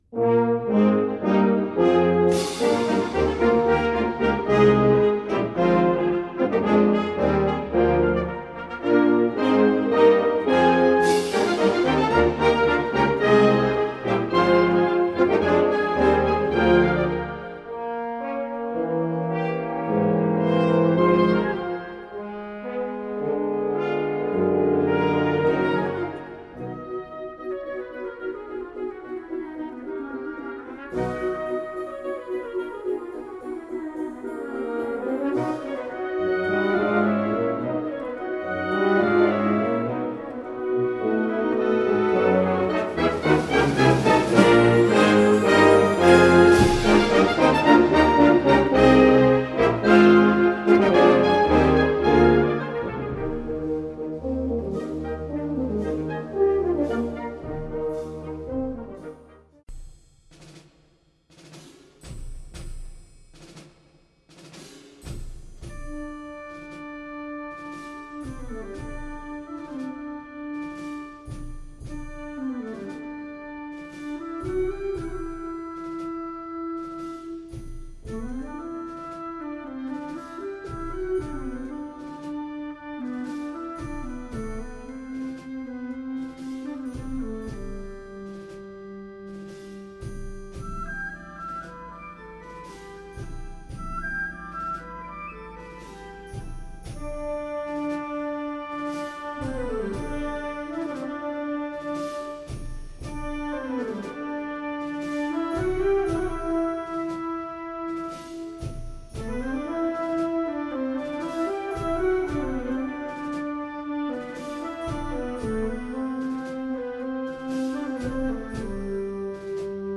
Categorie Harmonie/Fanfare/Brass-orkest
Bezetting Ha (harmonieorkest)